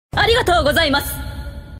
Arigato Gozaimasu With Echo